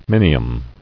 [min·i·um]